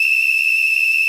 normal-sliderwhistle.wav